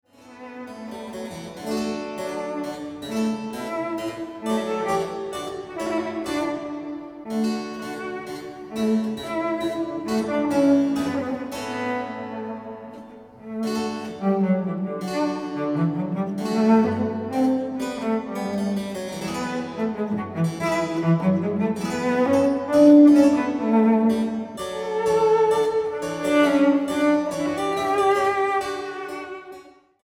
violoncelo